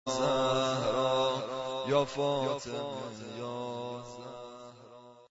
زمینه - شور